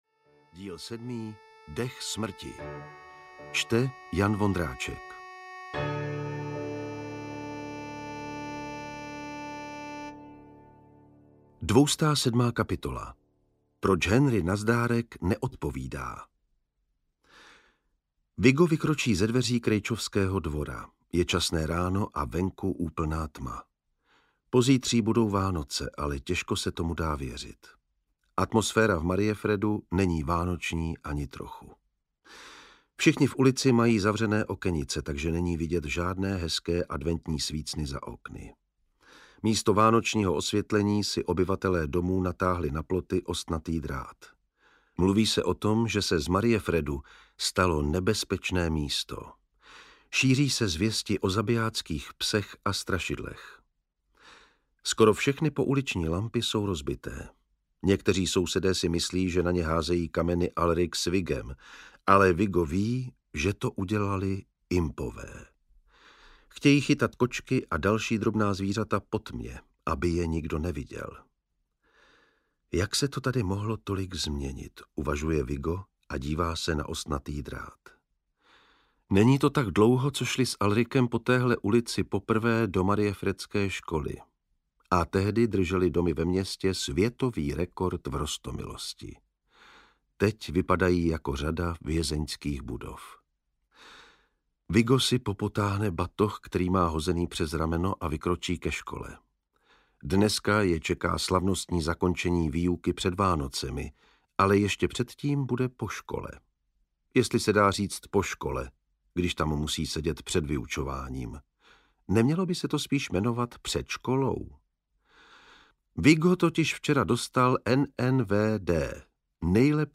Ukázka z knihy
• InterpretJan Vondráček
pax-7-dech-smrti-audiokniha